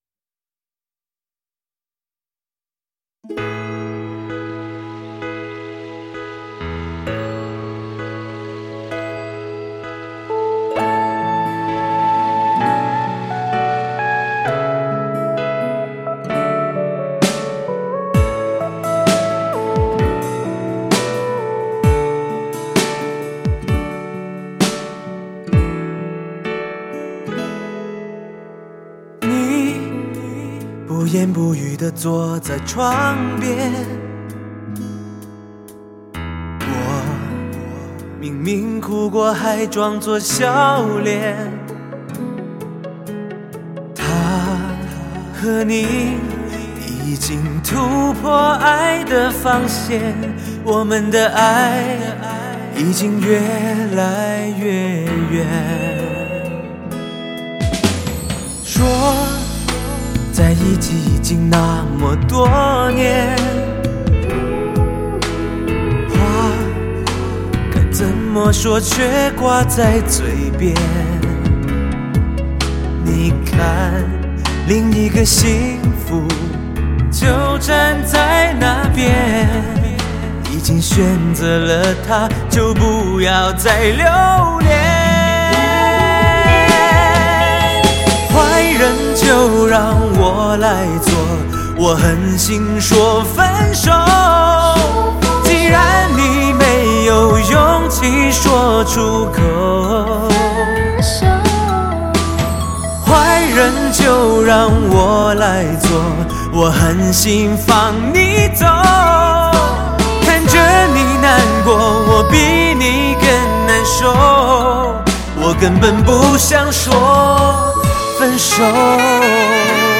此碟男声演唱功底扎实，声线情感丰富，音色醇厚、
干净、细腻、通透， 是近年来HI-FI男声特点的完美结合！